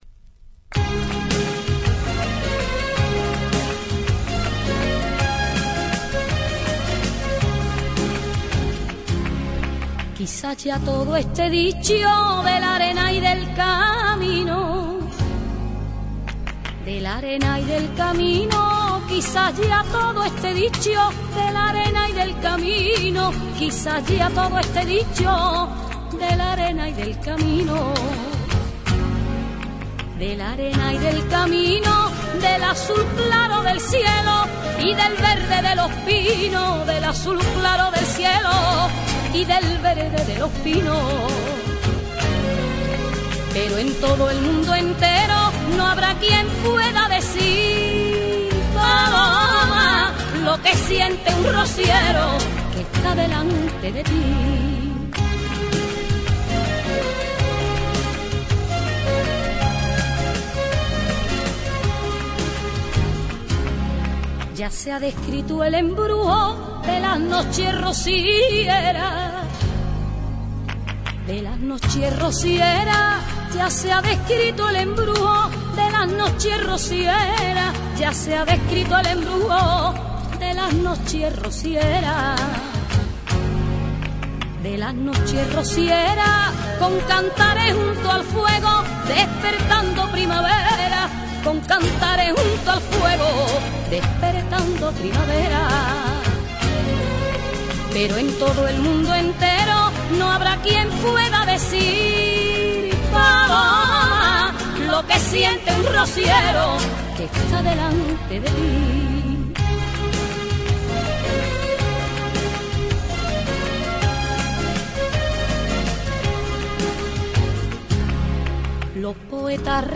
Temática: Rociera